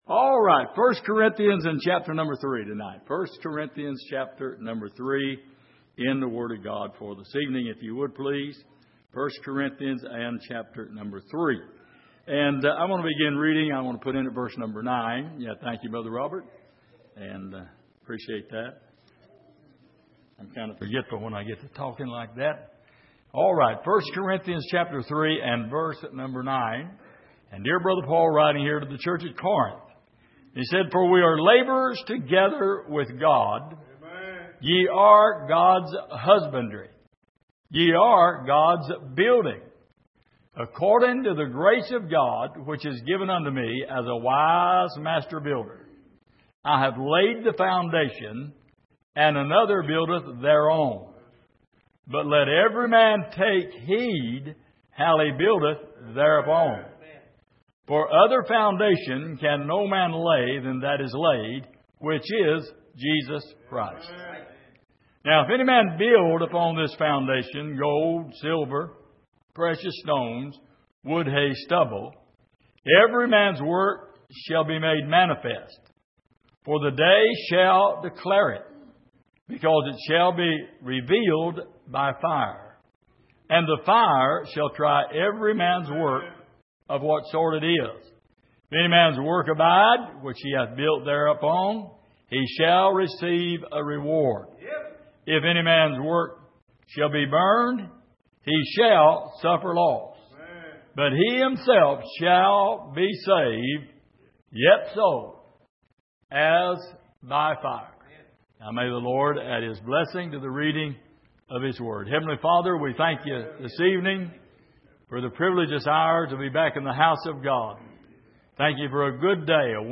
Passage: 1 Corinthians 3:9-15 Service: Sunday Evening